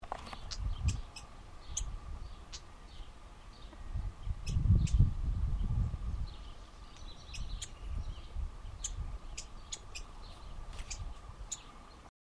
Bate-bico (Phleocryptes melanops)
Nome em Inglês: Wren-like Rushbird
Localidade ou área protegida: Valle Inferior del Río Chubut (VIRCH)
Condição: Selvagem
Certeza: Observado, Gravado Vocal